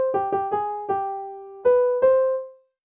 It goes like this: "Bah bah bah-bah bah...bah bah."
See, "Bah bah bah-bah bah...bah bah."